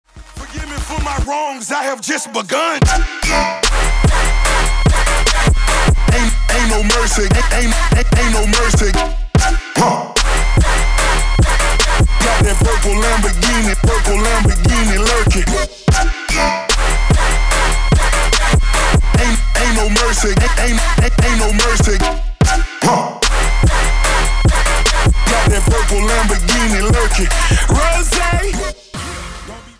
dubstep